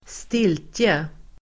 Uttal: [²st'il:tje]